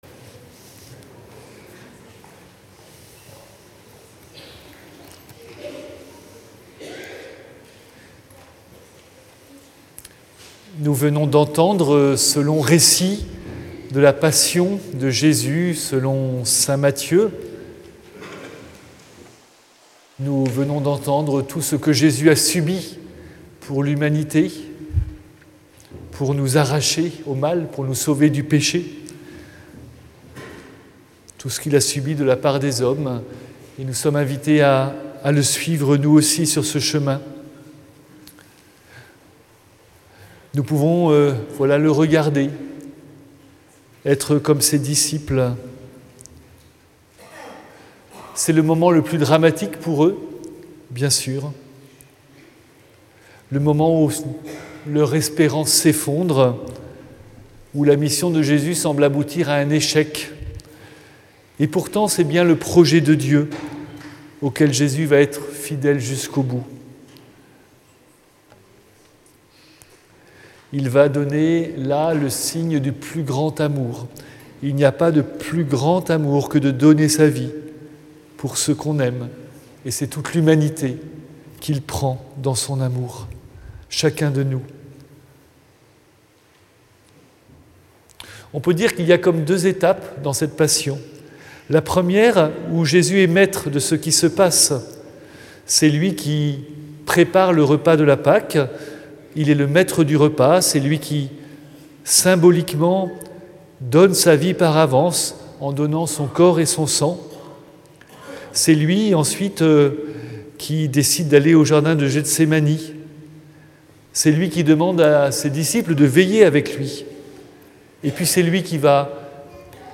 Audio : 2026-03-29 Homélie Rameaux du 28 mars 2026 à Plaisir